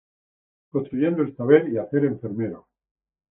en‧fer‧me‧ro
/enfeɾˈmeɾo/